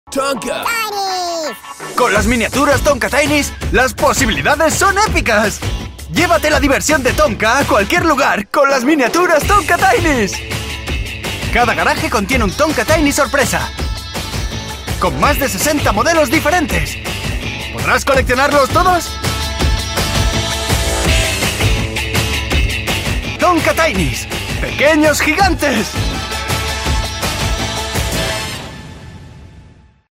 Spanish native actor (also Catalan) with professional experience in international studios with clients like Netflix, HBO, Cartoon Network, AXN, Calle 13, Films, Realitys With home-studio but acces to a professional one
kastilisch
Sprechprobe: Werbung (Muttersprache):